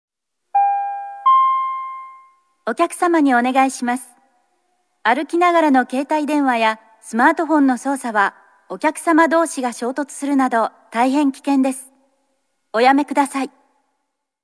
大阪メトロ(大阪市営地下鉄)の啓発放送